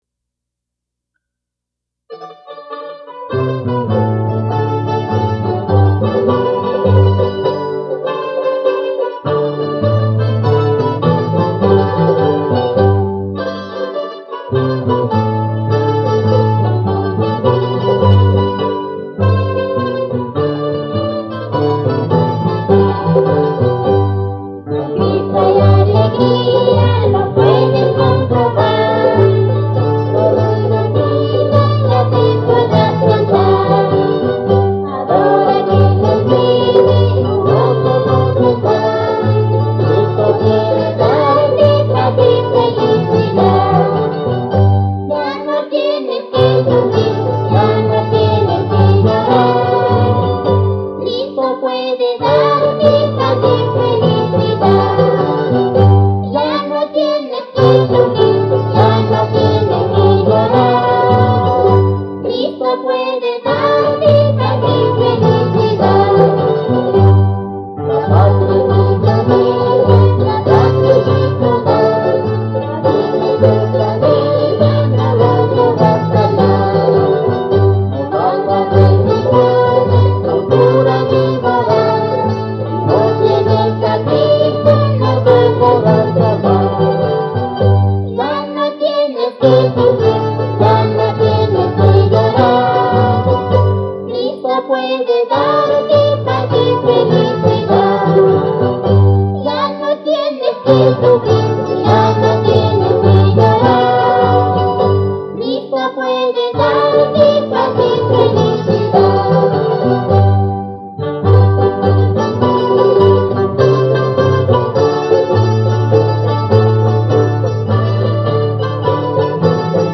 (Mandolina)
Guitarra
Charango
Vocalista